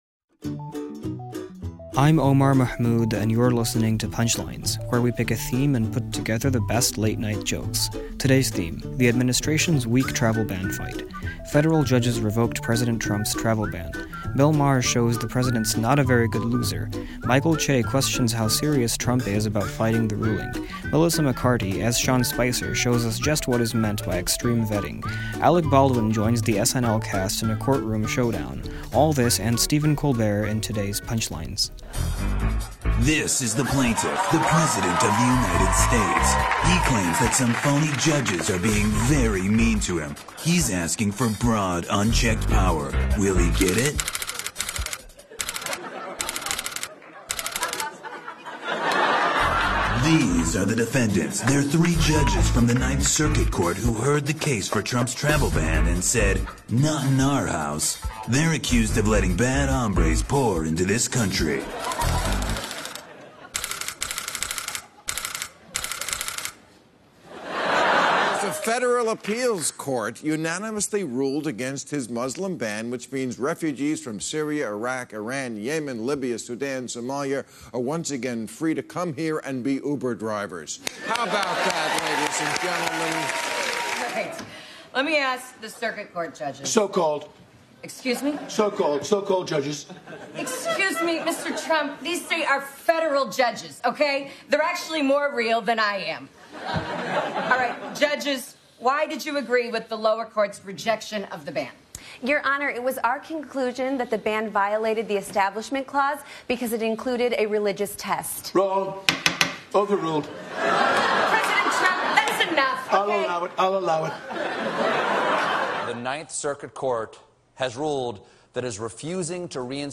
The late-night comics take a look at the ban on a ban.